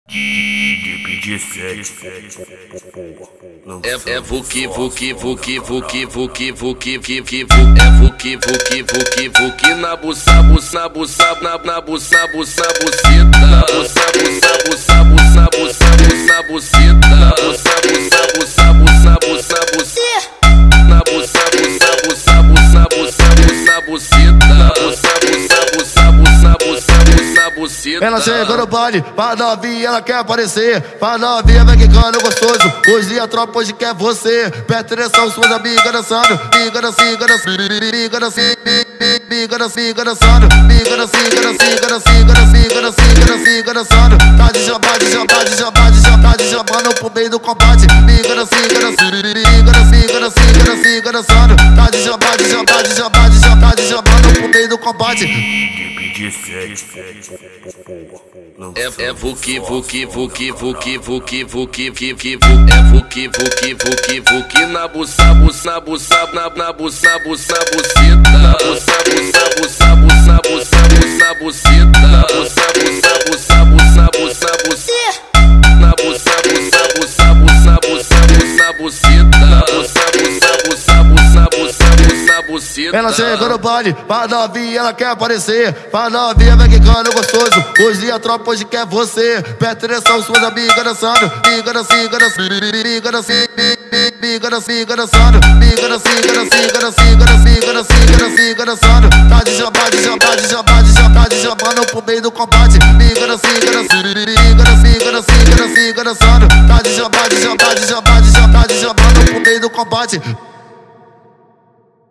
2024-06-07 23:28:46 Gênero: Rap Views